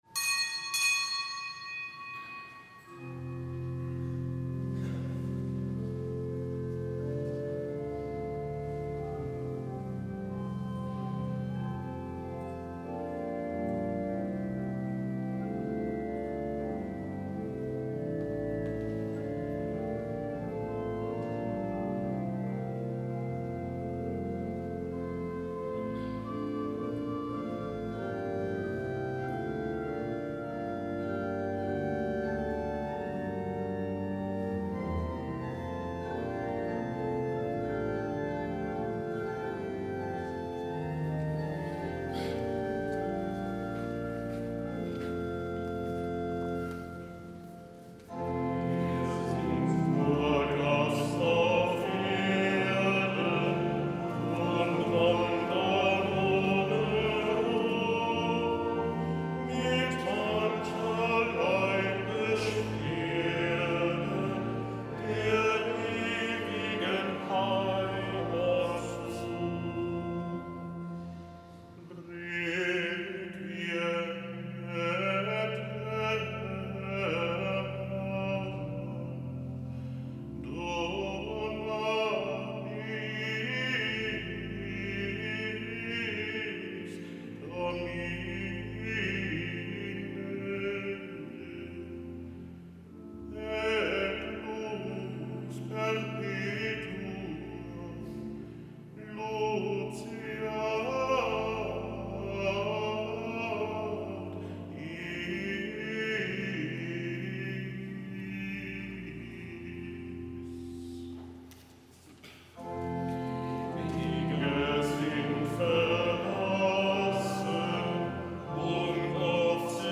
Kapitelsmesse aus dem Kölner Dom an Allerseelen.